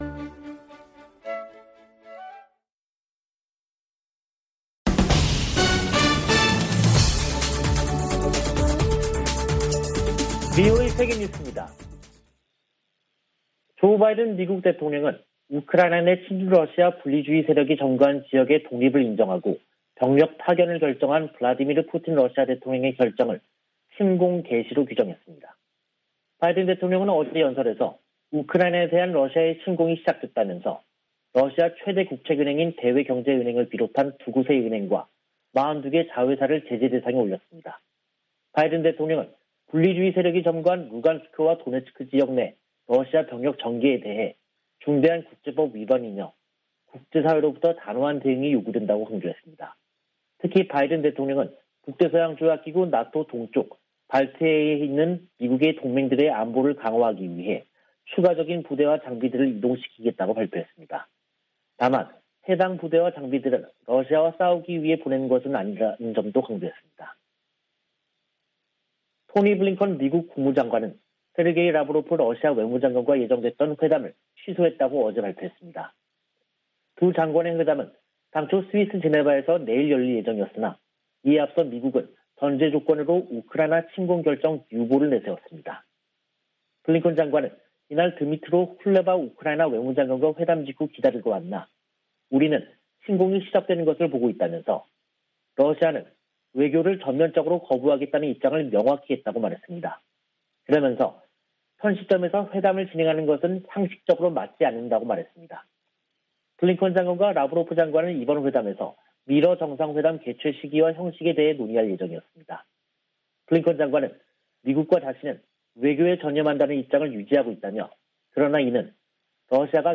VOA 한국어 간판 뉴스 프로그램 '뉴스 투데이', 2022년 2월 23일 2부 방송입니다. 최근 미국이 B-52 전략폭격기를 괌에 전개한 것은 인도태평양 역내 공격 억지를 위한 것이라고 기지 당국자가 밝혔습니다. 미국과 일본이 탄도미사일 방어에 초점을 둔 연례 연합훈련에 돌입했습니다. 한국인 70% 이상이 자체 핵무기 개발을 지지하는 것으로 나타났습니다.